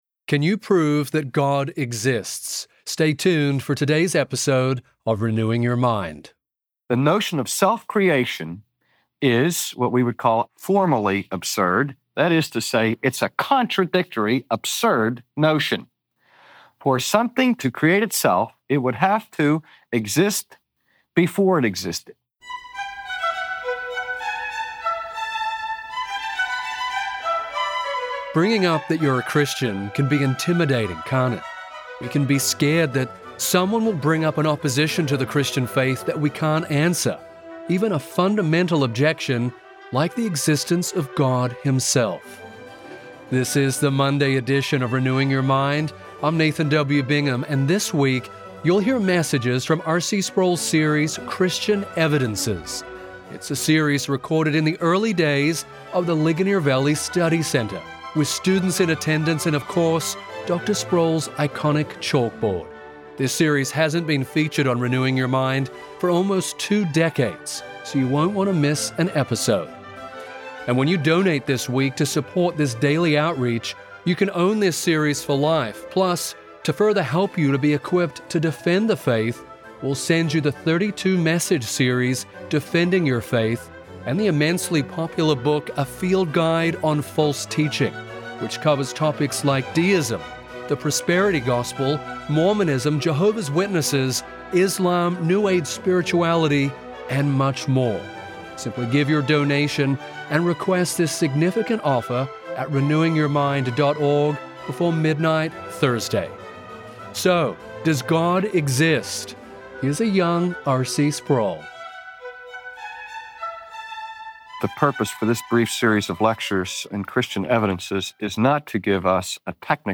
Today, R.C. Sproul equips Christians to defend their faith as he explains the necessity of a Creator to account for the world.